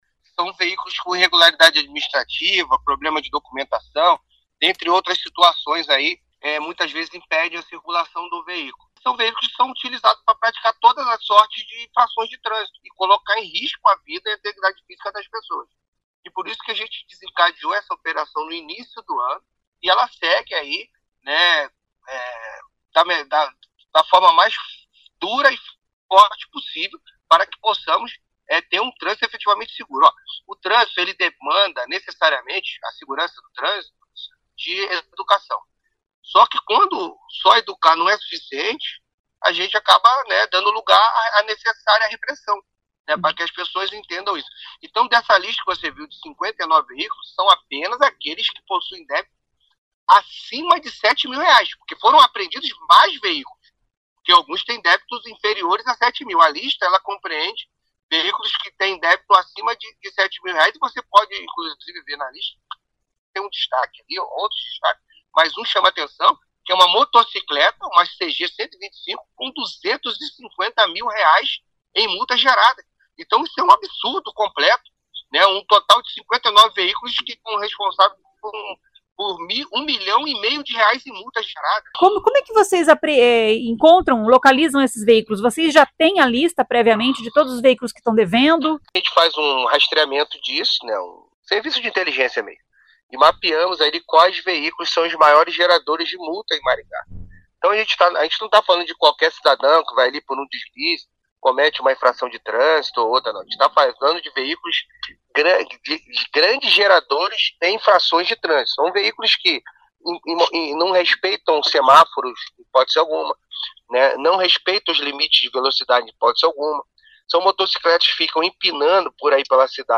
Ouça o que diz o secretário de Mobilidade Urbana, Luiz Alves: